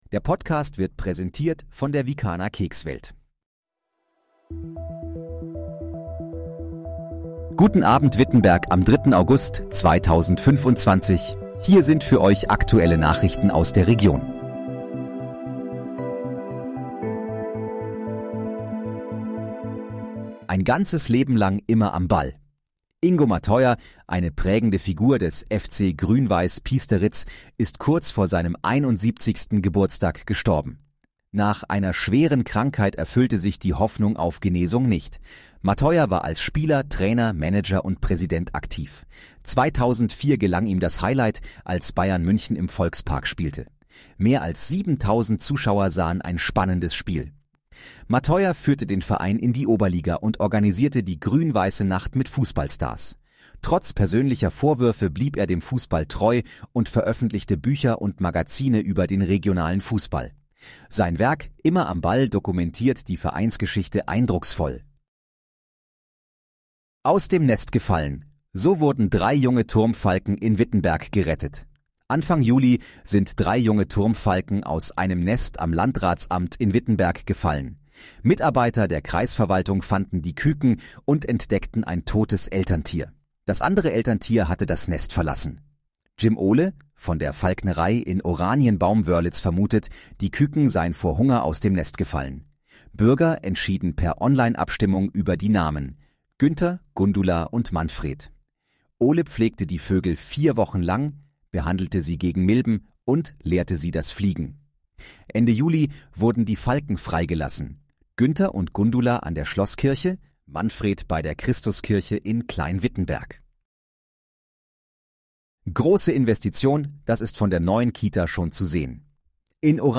Guten Abend, Wittenberg: Aktuelle Nachrichten vom 03.08.2025, erstellt mit KI-Unterstützung
Nachrichten